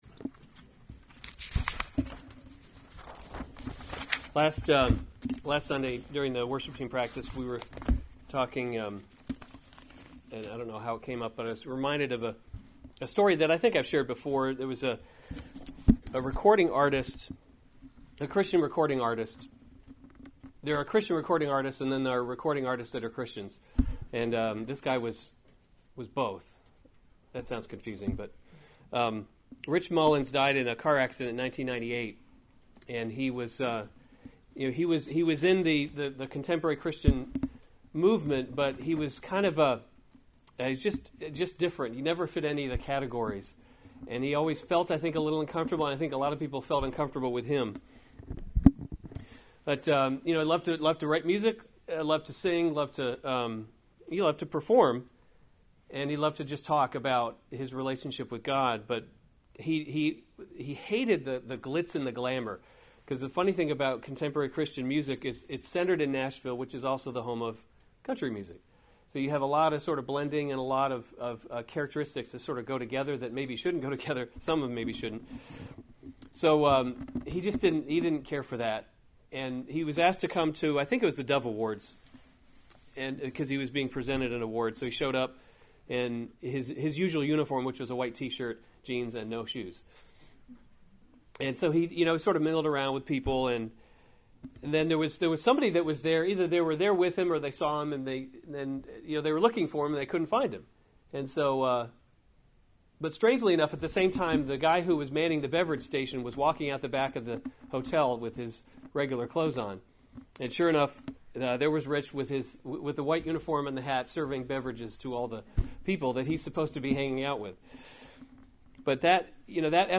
March 25, 2017 1 Timothy – Leading by Example series Weekly Sunday Service Save/Download this sermon 1 Timothy 3:8-13 Other sermons from 1 Timothy Qualifications for Deacons 8 Deacons likewise must […]